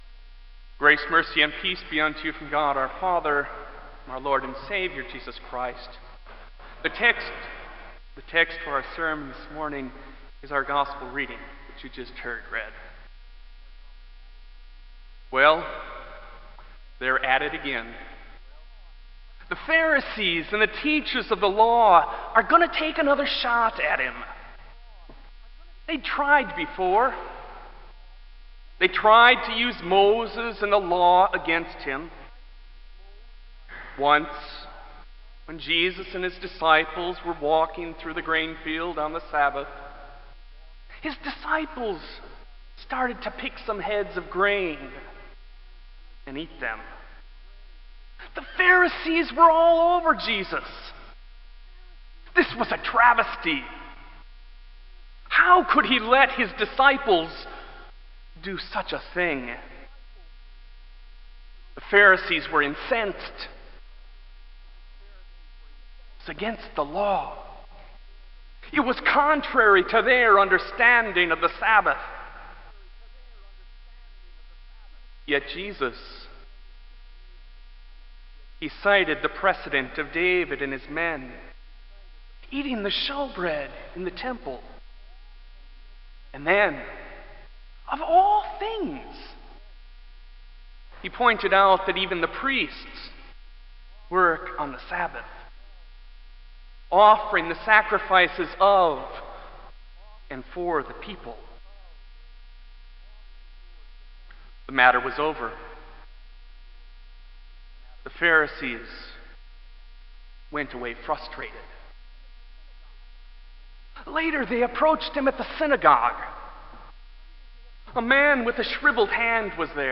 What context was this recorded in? Kramer Chapel Sermon - July 09, 2001